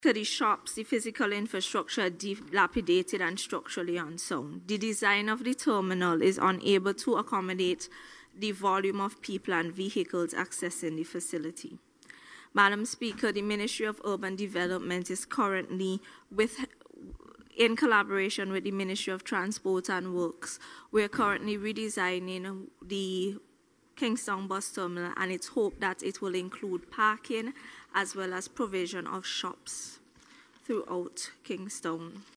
Minister of Urban Development Benarva Browne made the announcement while making her contribution to the 2025 budget debate in Parliament yesterday.